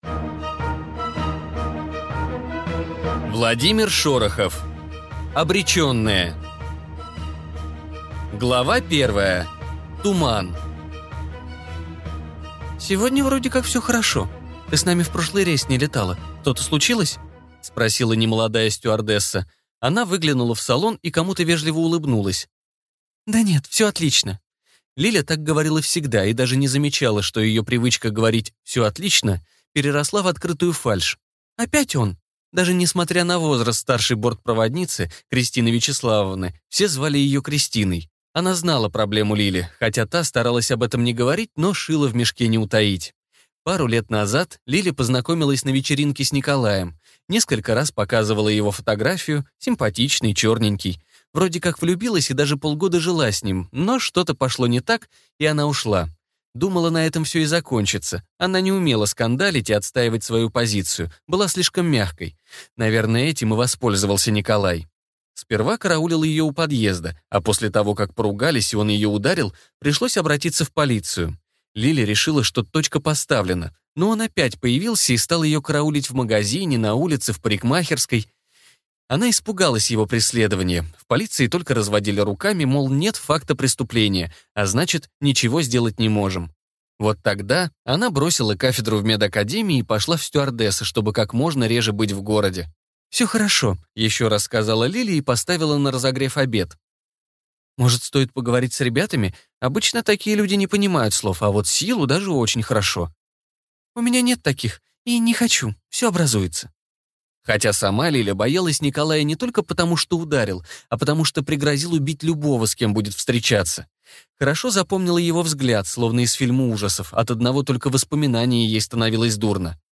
Аудиокнига Обреченные | Библиотека аудиокниг